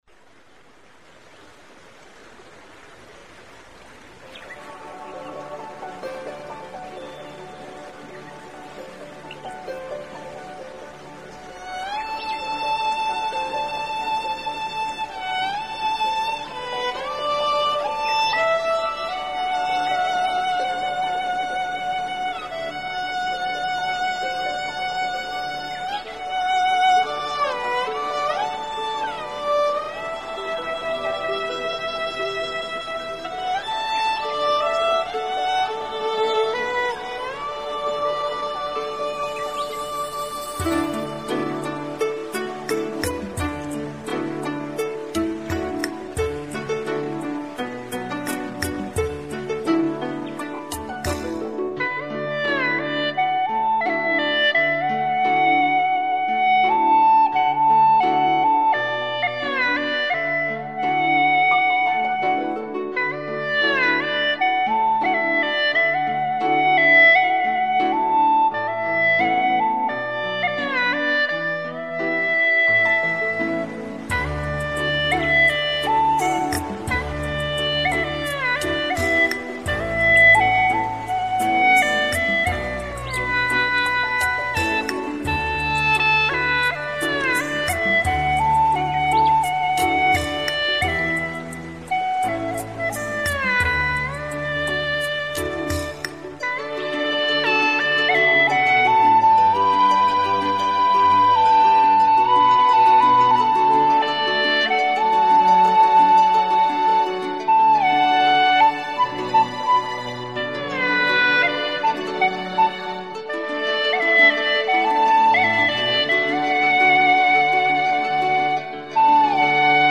调式 : D 曲类 : 独奏
傣味经典作品。用葫芦丝渲染恬静的夏夜，微风中，树木显得神秘幽邃，迷蒙的月光洒满林中，恍若仙境，令人向往，使人陶醉。